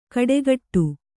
♪ kaḍegaṭṭu